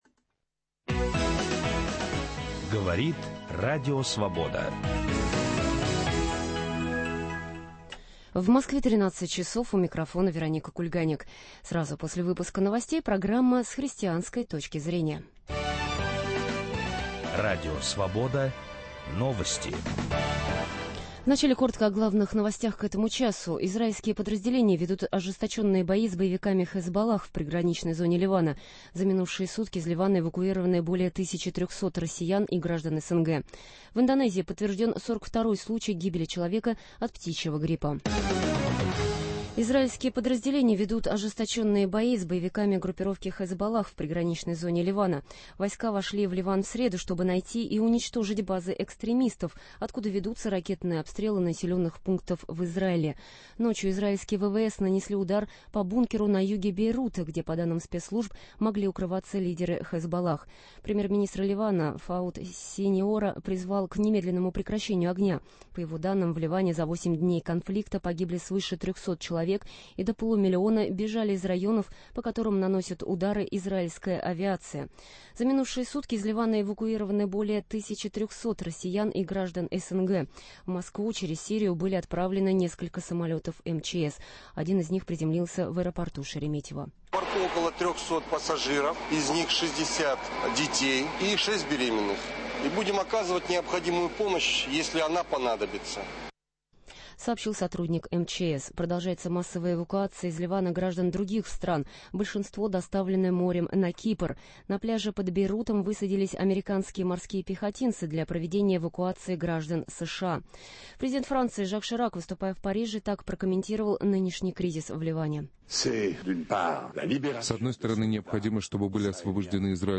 Талантов умер в тюрьме, но проблема жива: как отношения с Богом влияют на отношения государством - и наоборот? В передаче участвуют историки и современники тех событий.